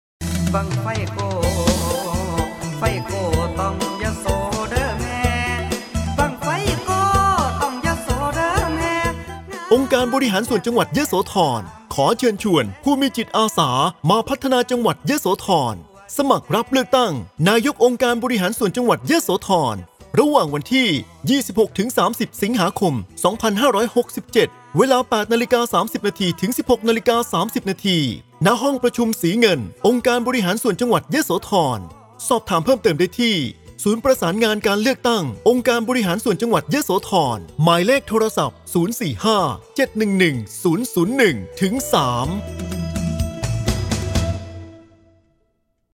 สปอตประชาสัมพันธ์รับสมัครเลือกตั้งนายกองค์การบริหารส่วนจังหวัดยโสธร